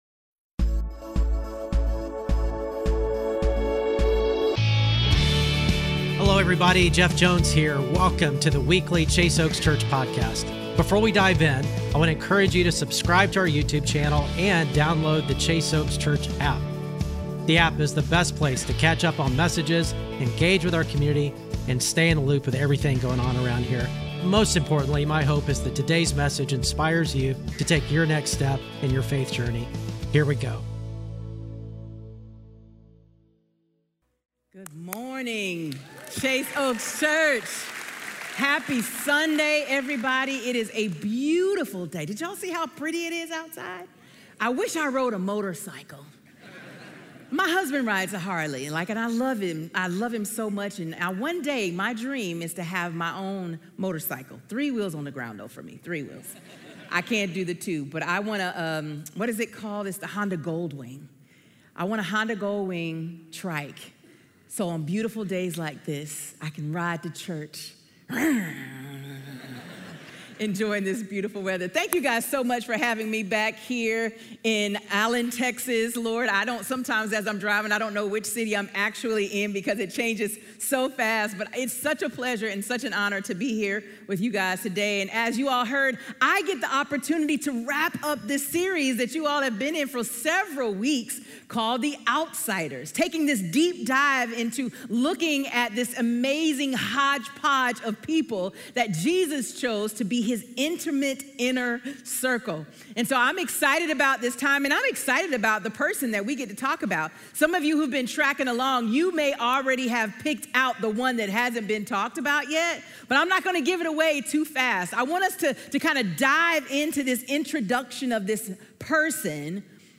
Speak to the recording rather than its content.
Weekly Sermons at Chase Oaks Church in Plano, Texas